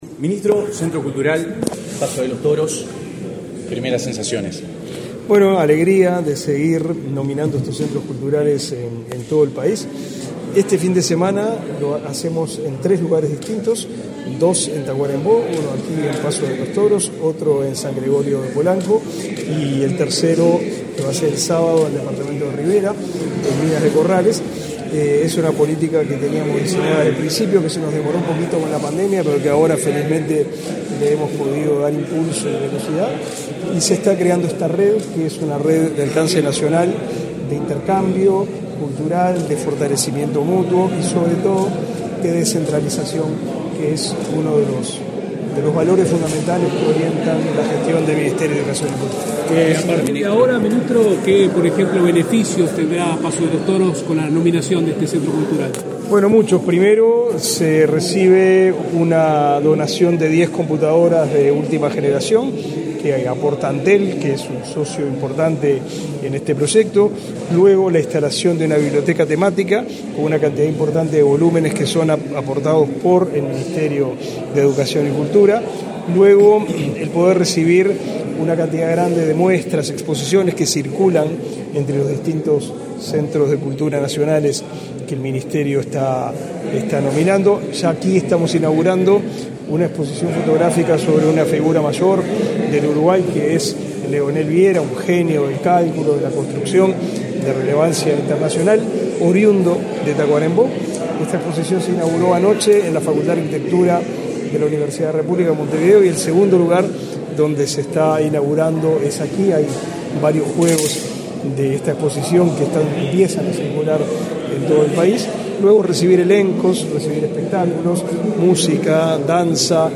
Declaraciones a la prensa del ministro del MEC, Pablo da Silveira
Tras participar en el acto de nominación como Centro Cultural Nacional a la Casa de la Cultura de Paso de los Toros, en el departamento de Tacuarembó,